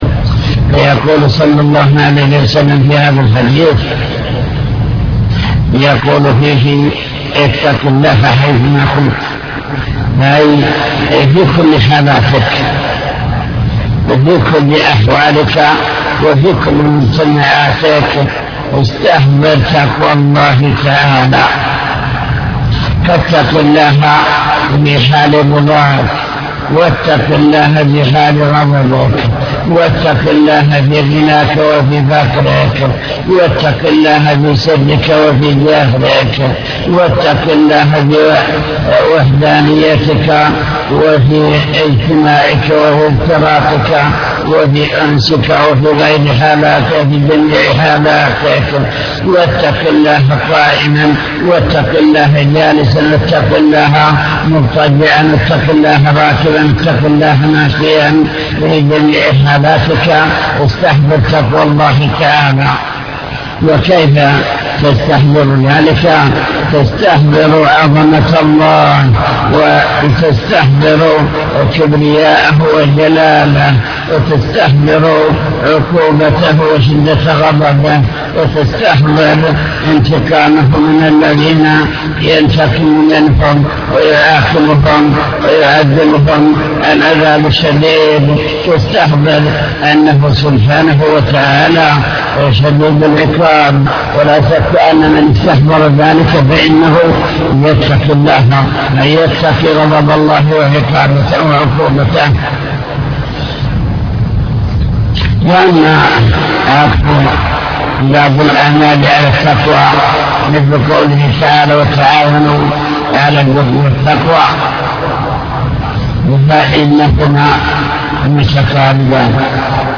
المكتبة الصوتية  تسجيلات - كتب  شرح كتاب بهجة قلوب الأبرار لابن السعدي شرح حديث اتق الله حيثما كنت